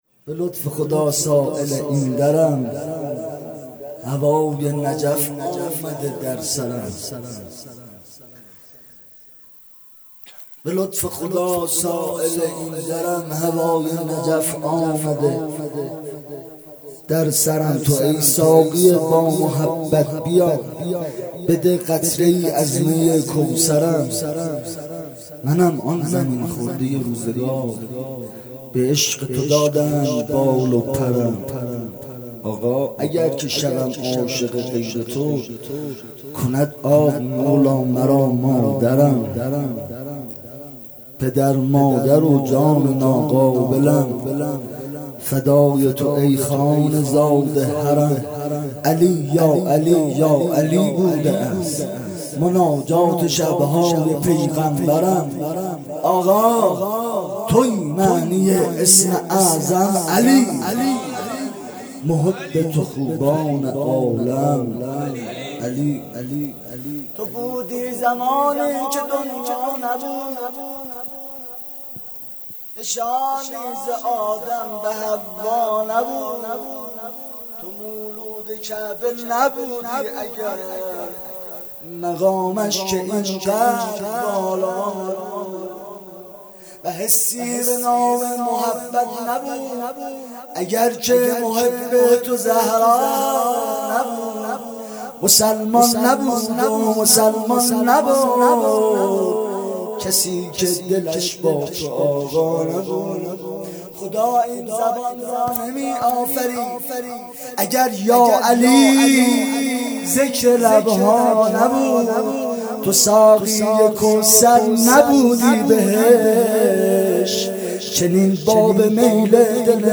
بخش دوم-مدح